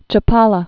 (chə-pälə)